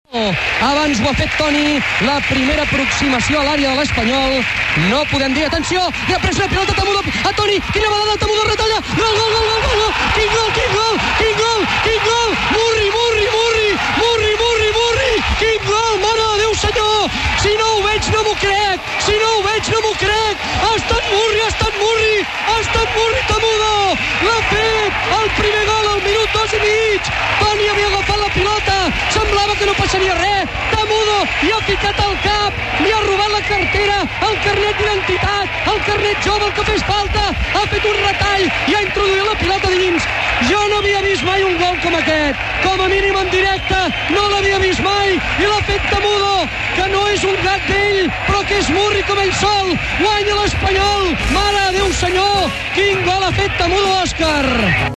Narración del gol de Tamudo en Catalunya Radio